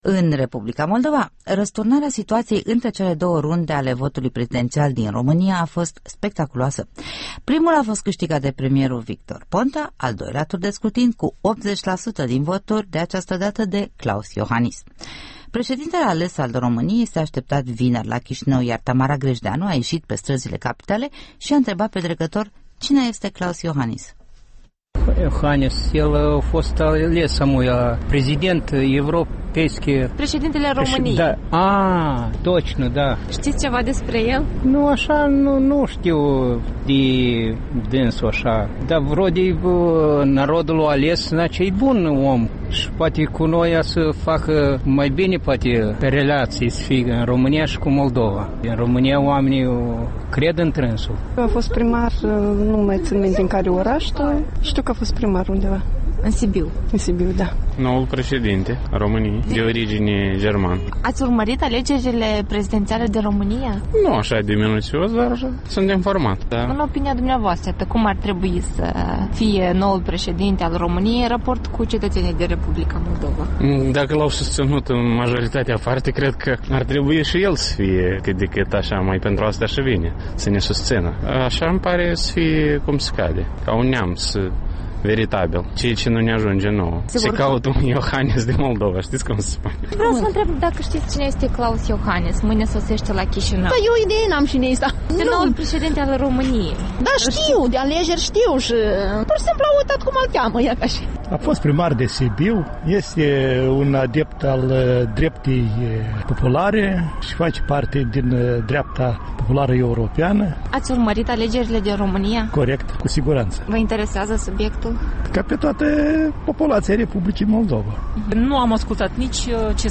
Un vox pop pe străzile Chișinăului